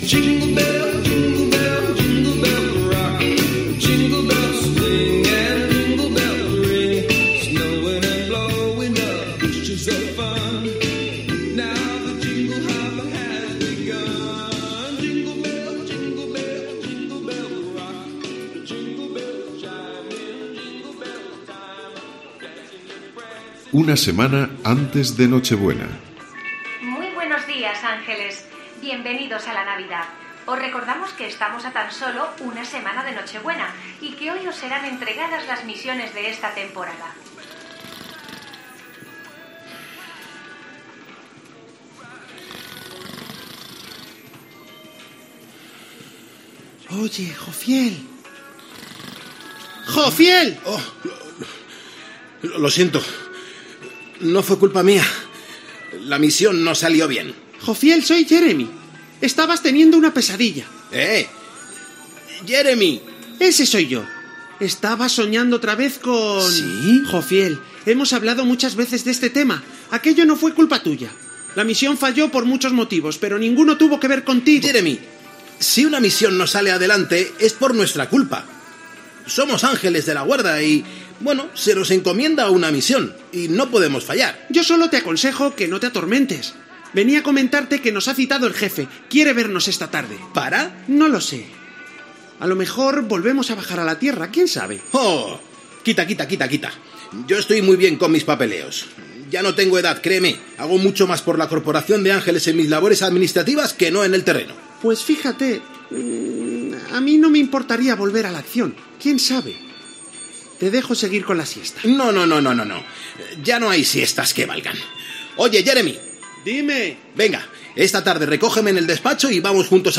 eec086d4346a554fe81aa89de7ea89bd1a243c74.mp3 Títol Cadena COPE - Cuento de Navidad Emissora COPE Barcelona Cadena COPE Titularitat Privada estatal Nom programa La nieve Descripció Ficció de Nadal.